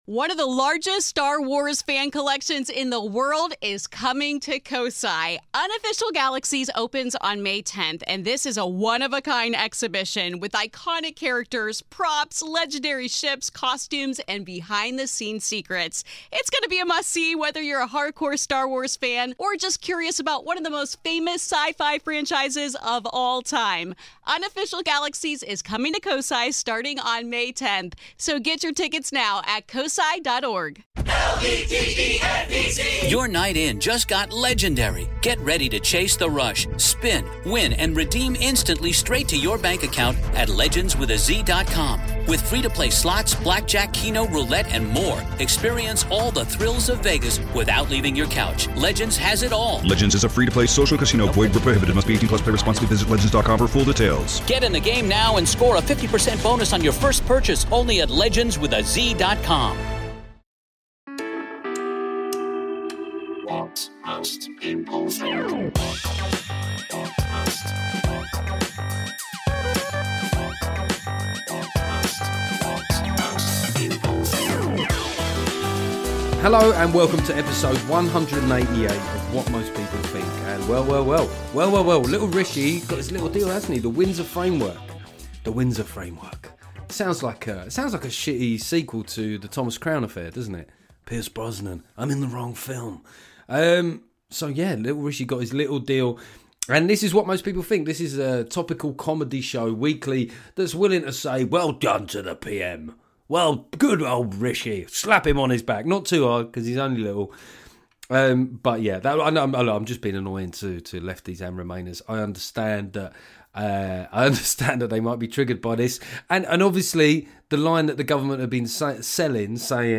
Then I speak to Scottish rapper, activist and writer Darren McGarvey.